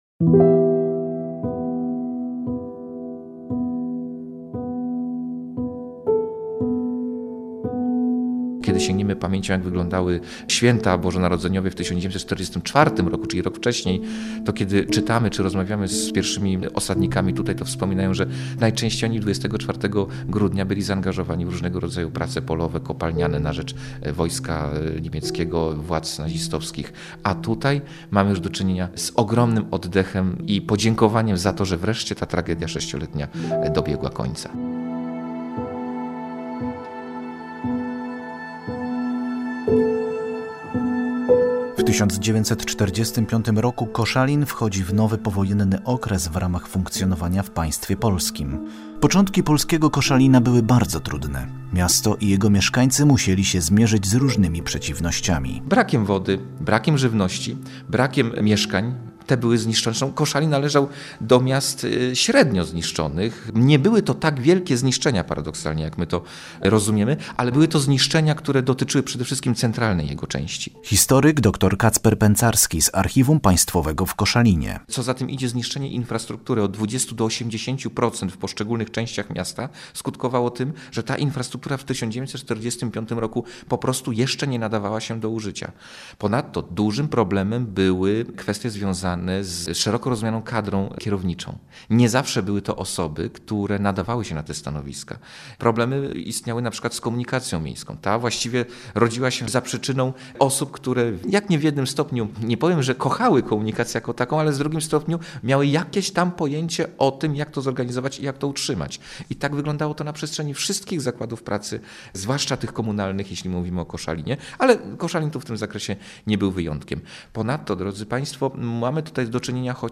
Jak wyglądały pierwsze polskie Święta Bożego Narodzenia w Koszalinie i Kołobrzegu? Z jakimi problemami w powojennych latach borykali się pionierzy Koszalina i jak wspominają tamten czas? Na te i na inne pytania znajdziecie Państwo odpowiedź w rozmowie red.